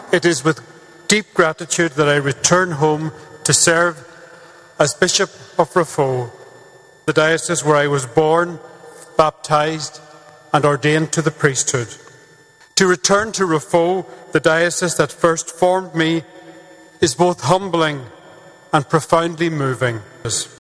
Speaking at the cathedral on the day of the announcement, Bishop Coll said his appointment was both moving and humbling………….